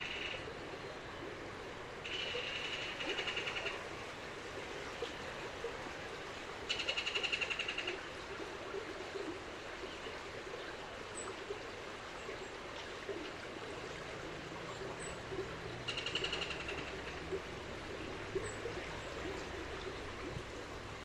Ringed Kingfisher (Megaceryle torquata)
Life Stage: Adult
Location or protected area: Parque Nacional Nahuel Huapi
Detailed location: Seccional Lago Gutiérrez
Condition: Wild
Certainty: Observed, Recorded vocal